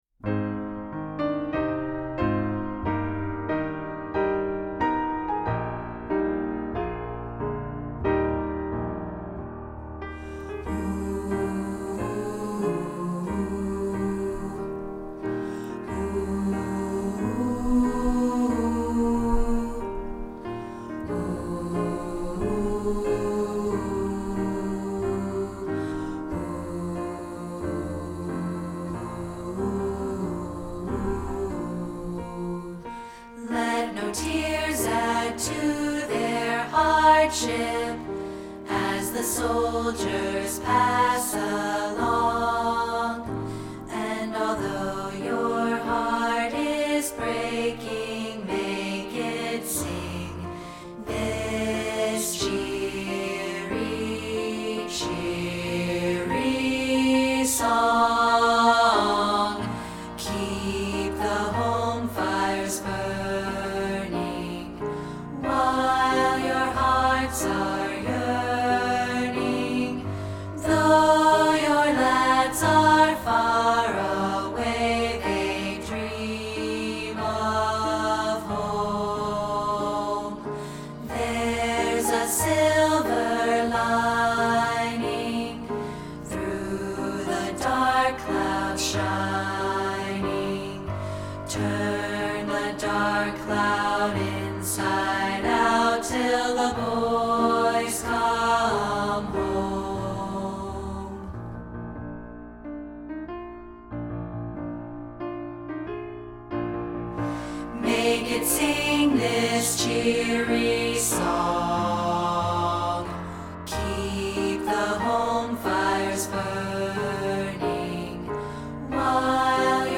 This is a rehearsal track of part 3, isolated.